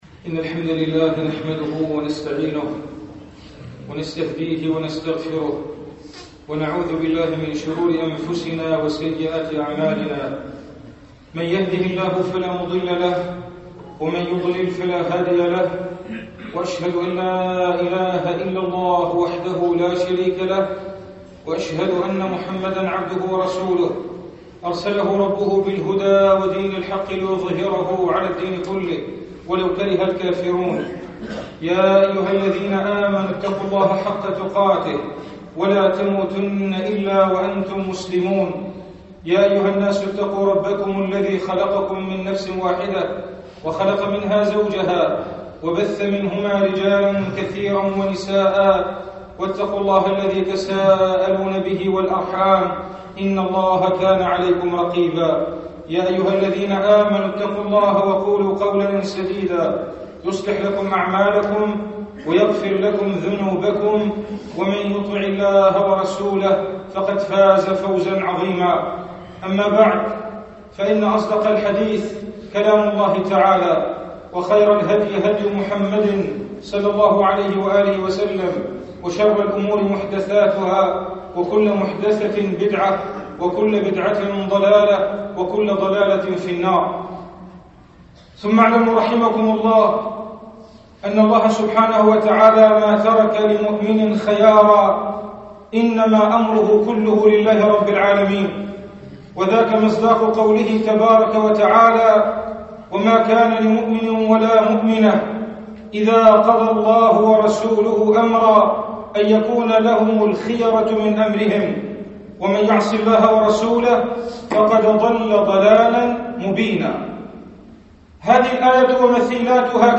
خطبة الجمعة من جامع القلمون الكبير (البحري)